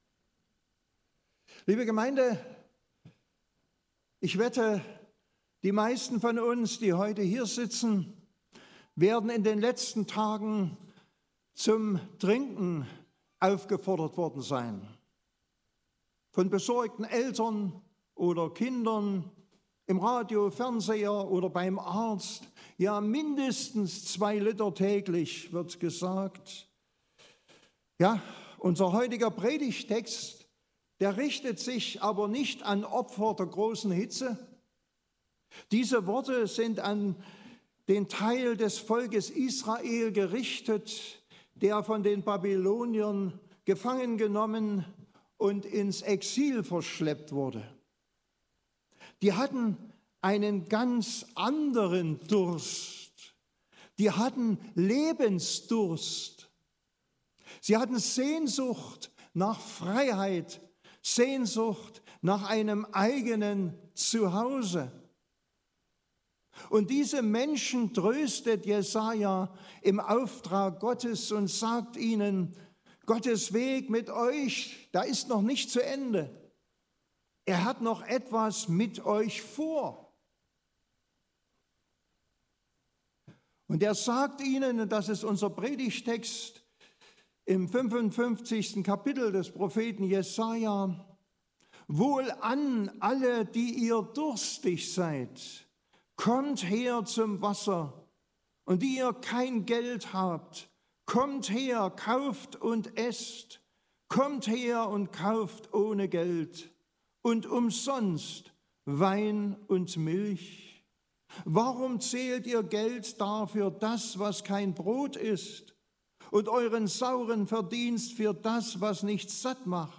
1-3 Gottesdienstart: Predigtgottesdienst Obercrinitz Jesaja richtet hoffnnungsvolle Worte an das Volk Israel im Exil.